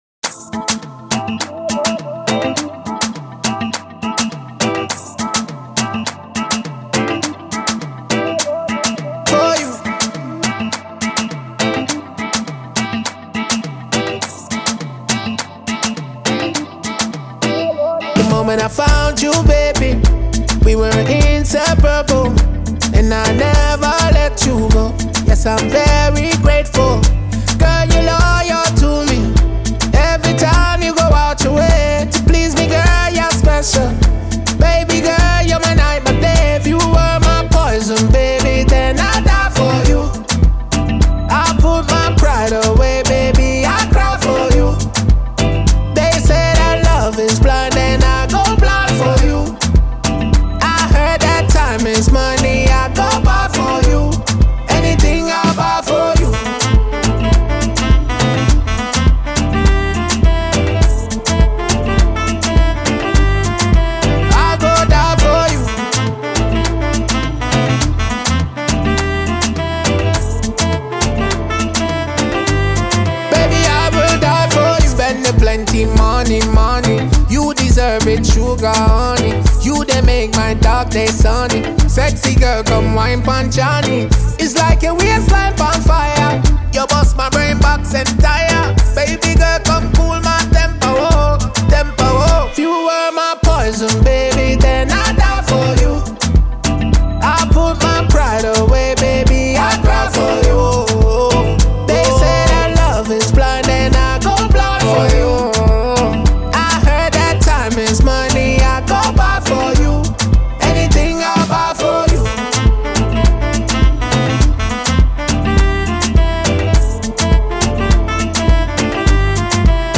Ace Jamaican dancehall musician